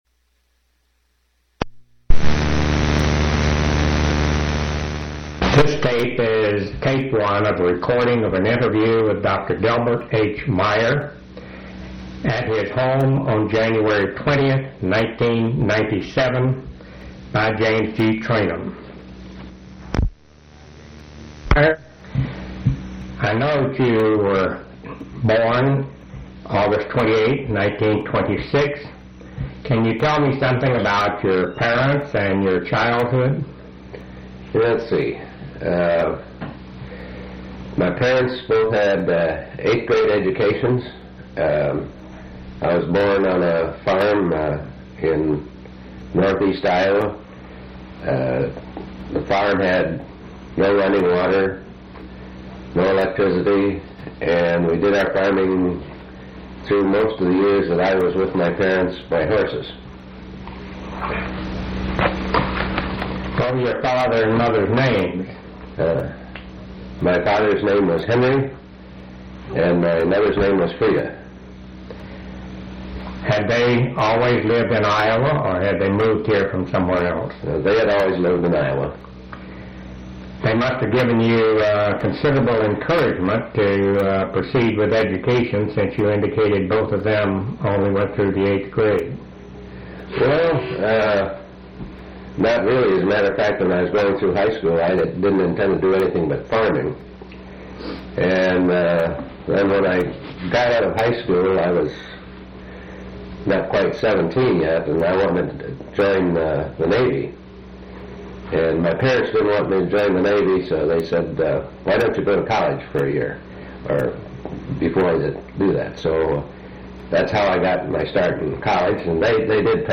Genre Oral histories